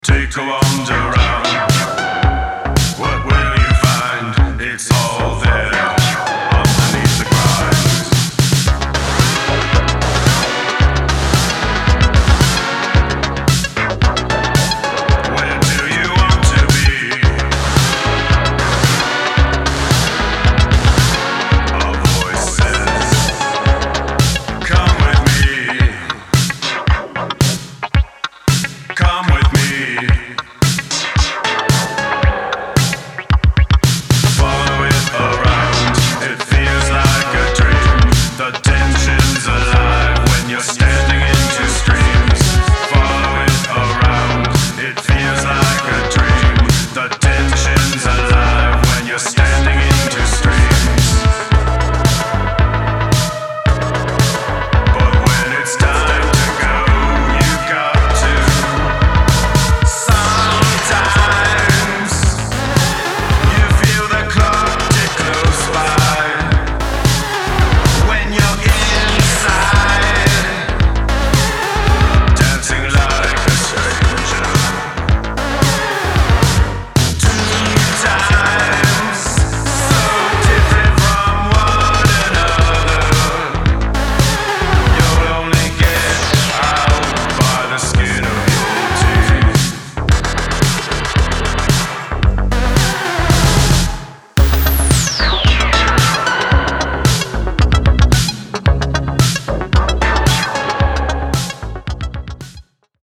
an impressive demonstration of synth-craft and programming